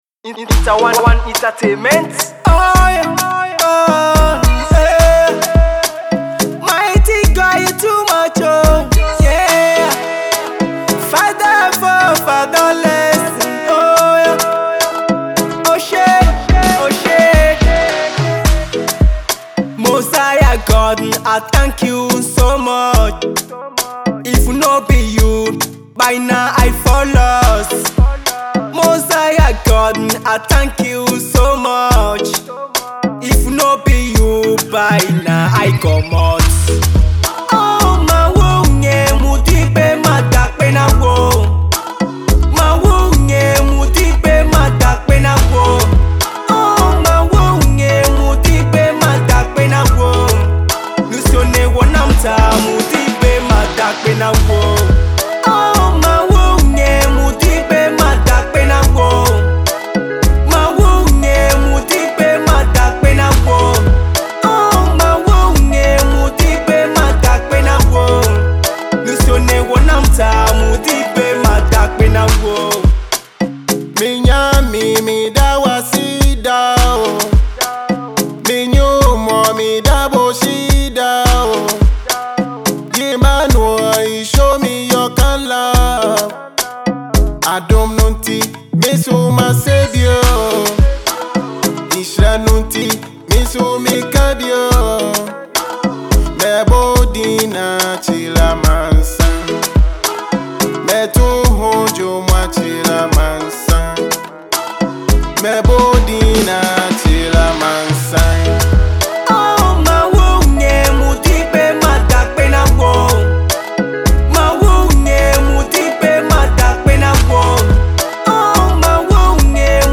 gospel tune